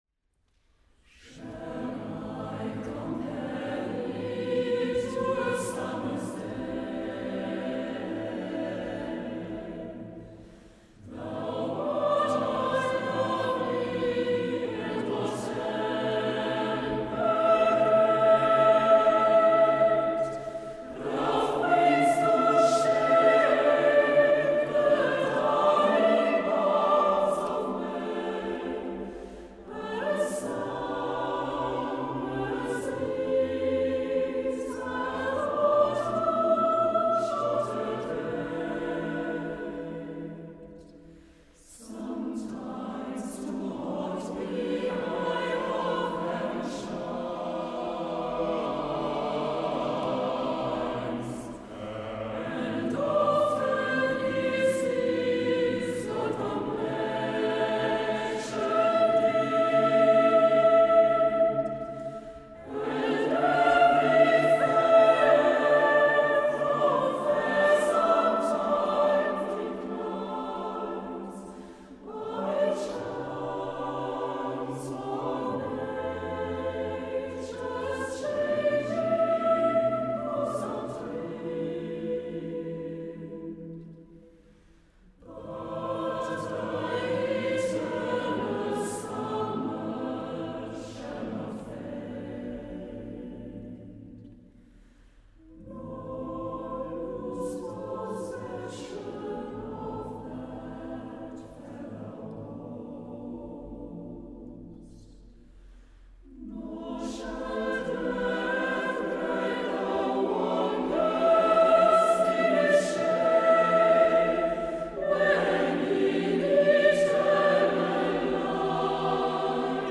Live-Mitschnitte Konzerte 2023